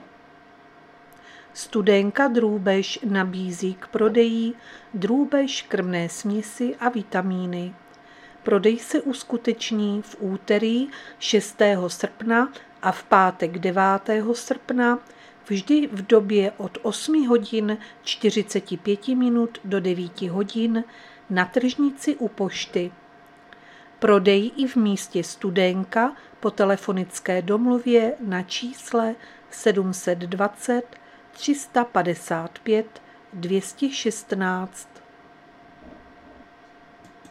Zařazení: Rozhlas